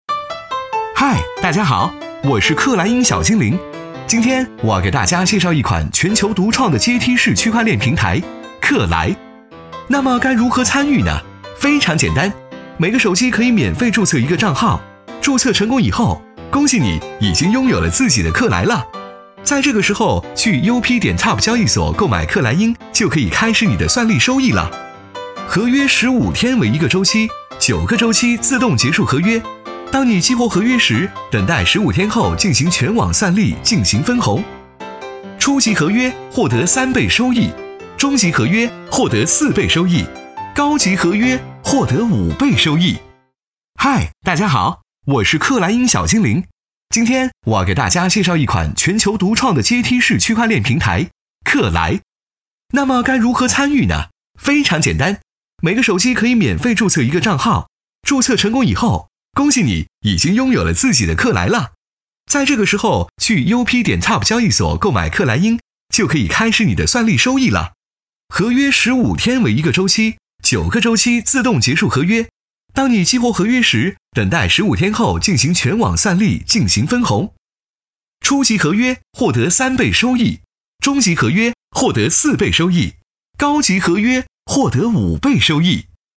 8 男国311_宣传片_病毒_克莱 男国311
男国311_宣传片_病毒_克莱.mp3